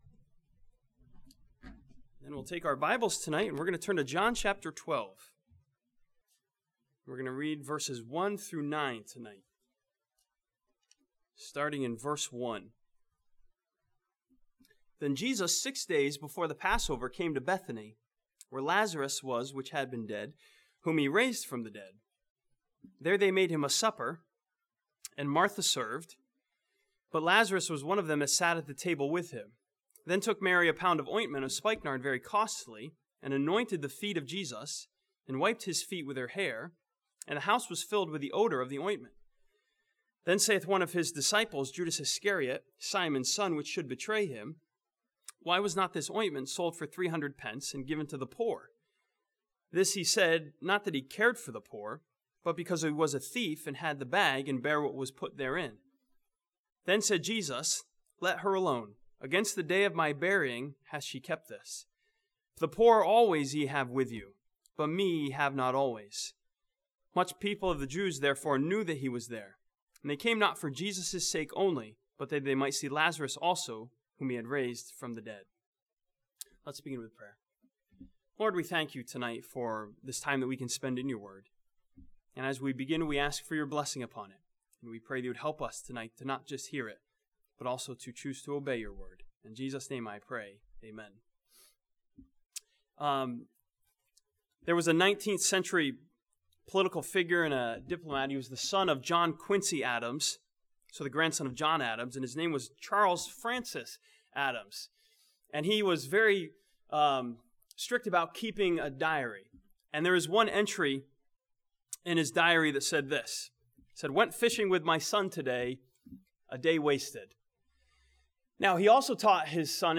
This sermon from John chapter 12 examines Judas' claim that Mary's offering was a waste and challenges believers to give to the Lord.